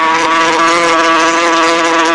Hornet Sound Effect
Download a high-quality hornet sound effect.
hornet.mp3